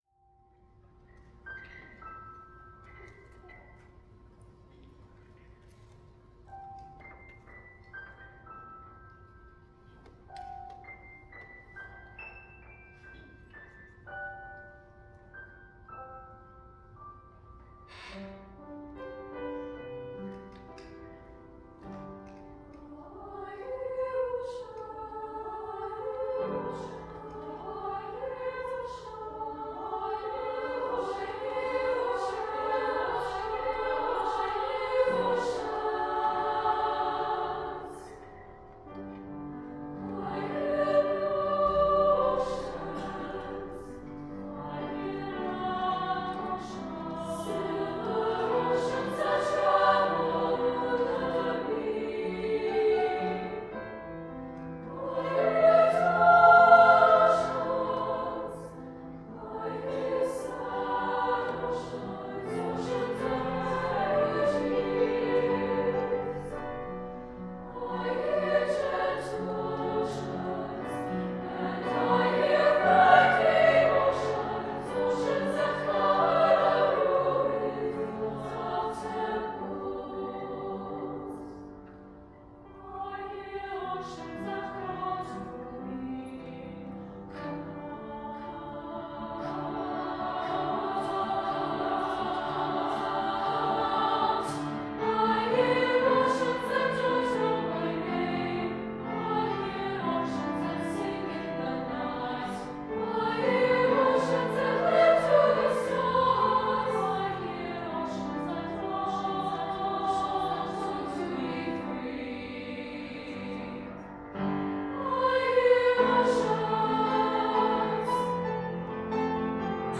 Chamber Women’s Choir: I Hear Oceans – Jacob Narverud
22_i-hear-oceans_chamber-womens-choir.mp3